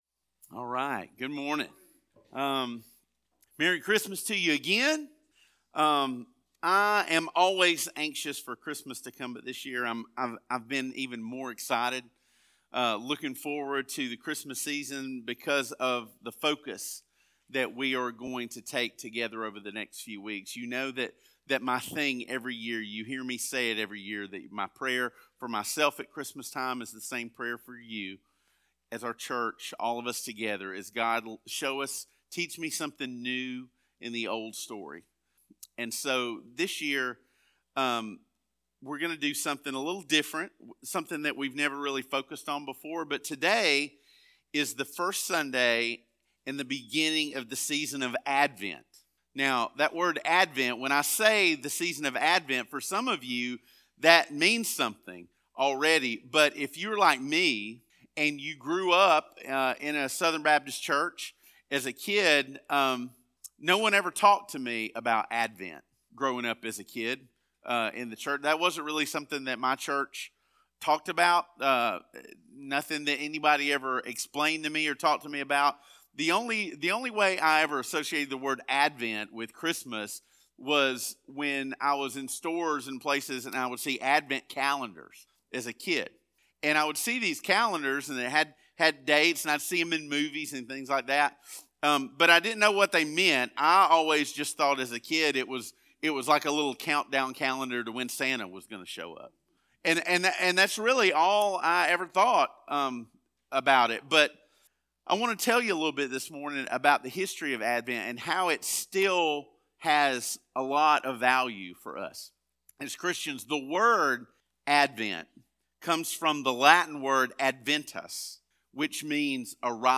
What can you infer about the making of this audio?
Welcome to the official podcast of our weekly Sunday morning sermons.